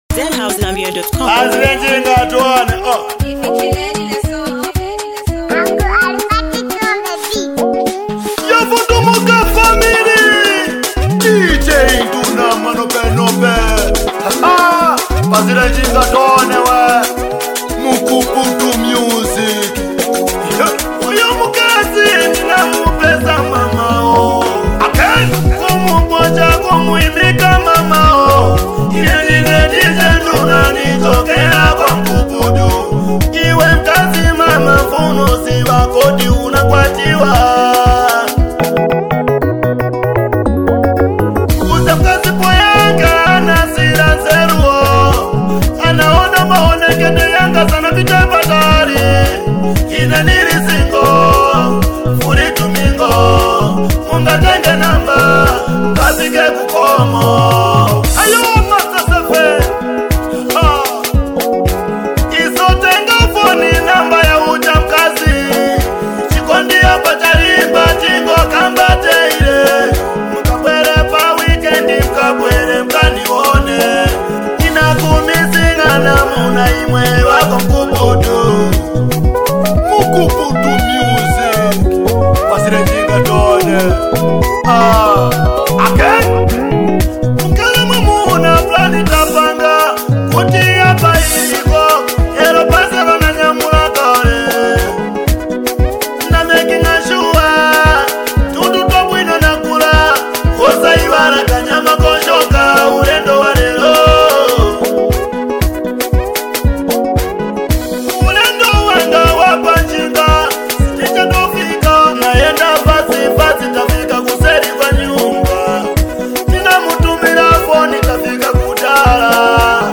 a thunderous street banger pulsing with raw intensity.